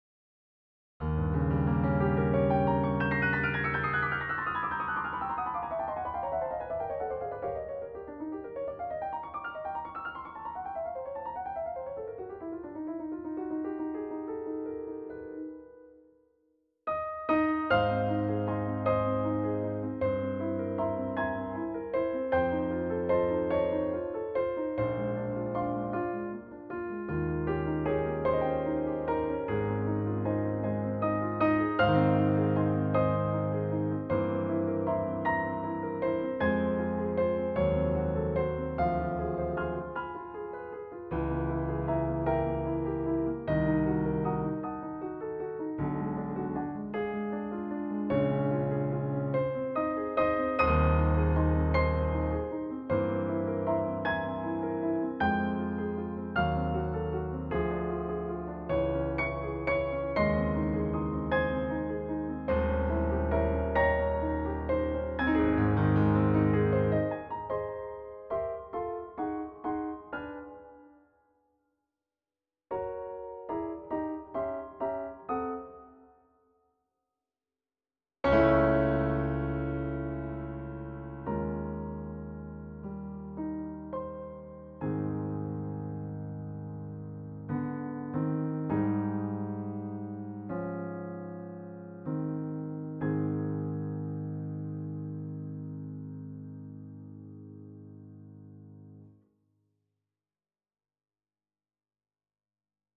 with a 19th century twist